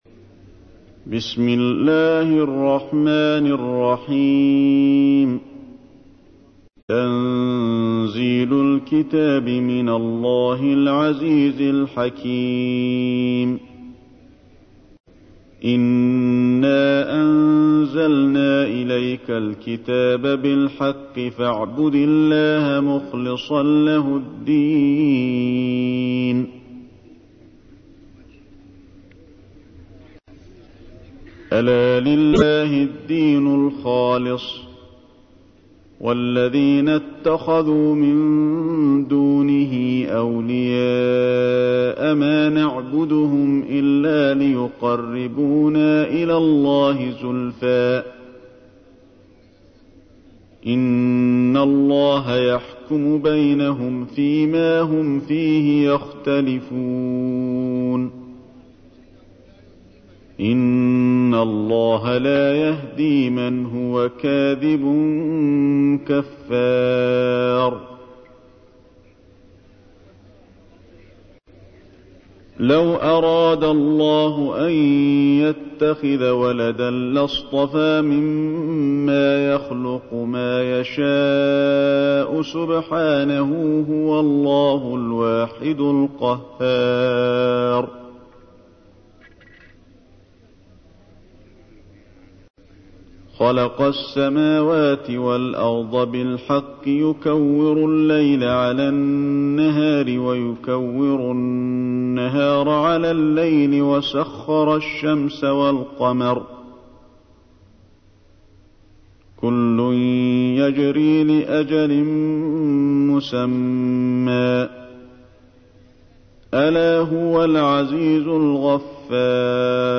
تحميل : 39. سورة الزمر / القارئ علي الحذيفي / القرآن الكريم / موقع يا حسين